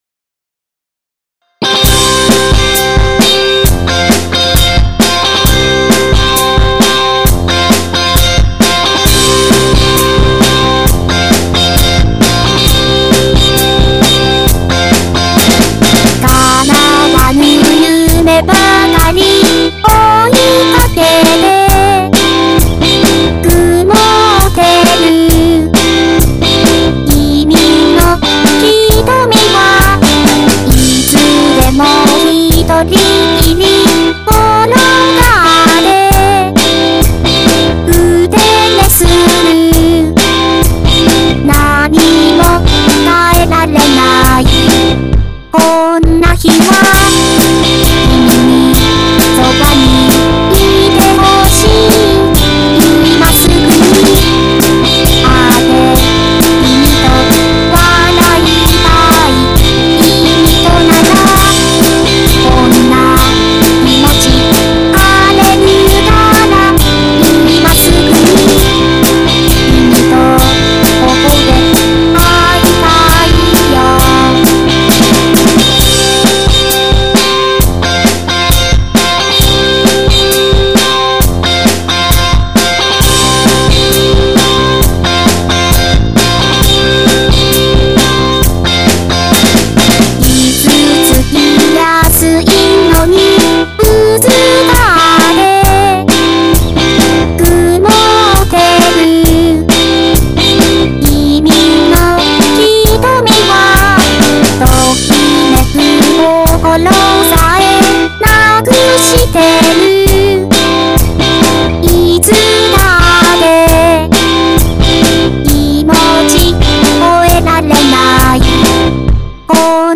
打ち込みで作った創作メインの音楽をアップしてます。
ごくオーソドックスなアレンジ、
メロ重視で、サビから発展させた、
アップテンポな曲、